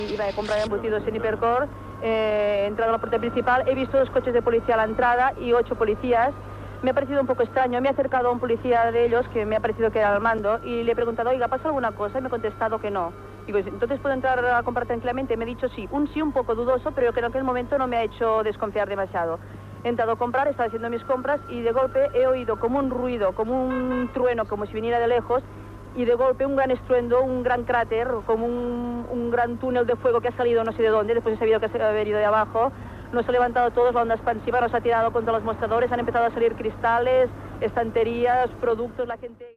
Declaració d'una testimoni presencial de l'atemptat a l'Hipercor de la Meridiana,a a Barcelona
Informatiu